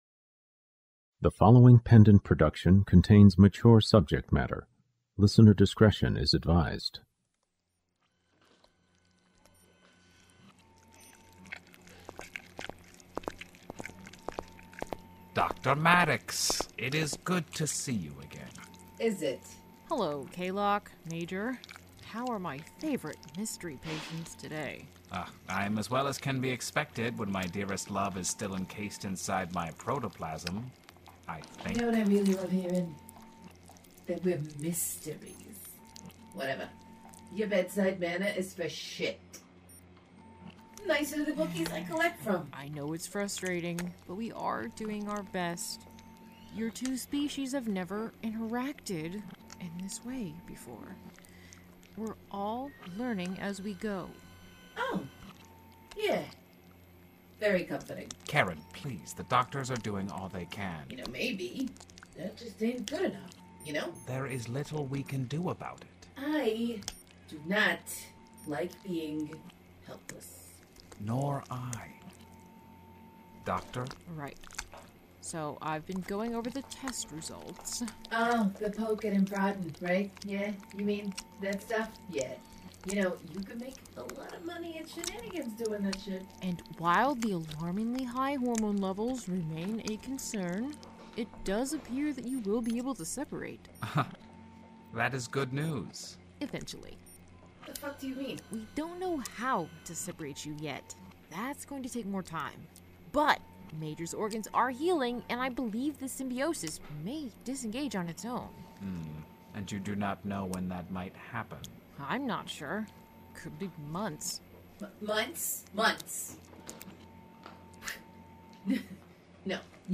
The Kingery sci-fi crime audio drama